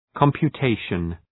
Προφορά
{,kɒmpjə’teıʃən}